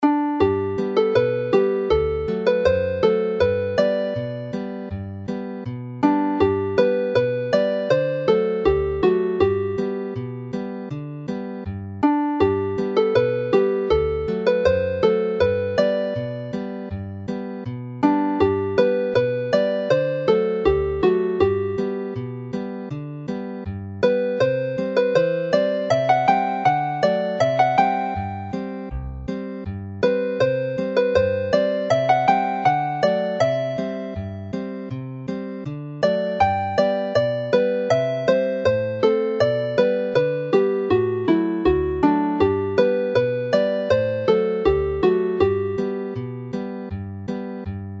Rich (yellow) Cream - 32 bar adaptation
Play the tune slowly